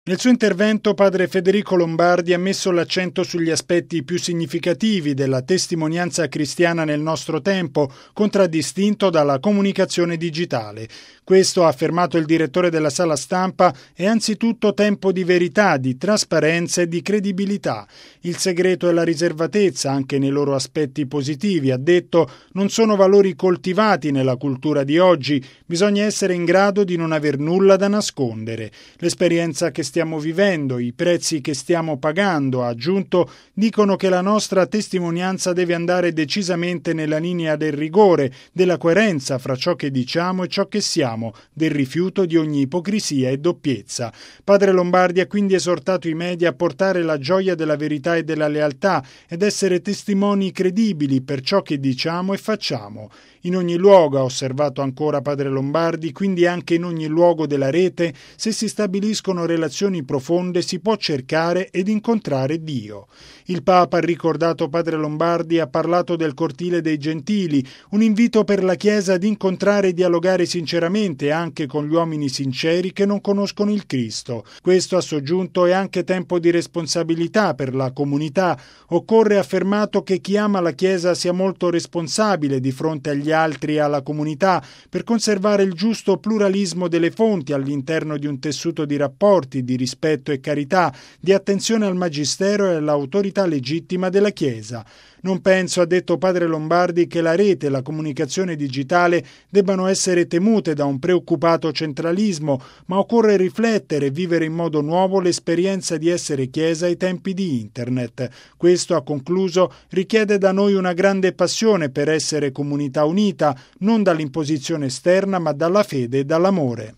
Nel suo intervento, padre Federico Lombardi ha messo l’accento sugli aspetti più significativi della testimonianza cristiana nel nostro tempo, contraddistinto dalla comunicazione digitale.